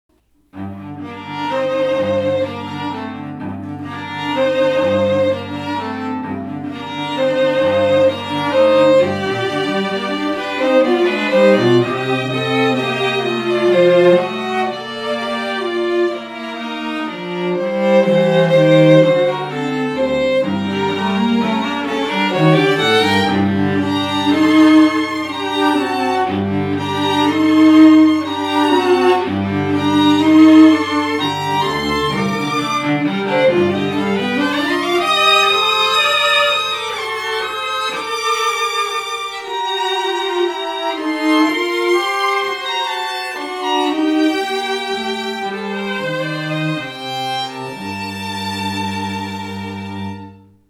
STRING TRIO SAMPLES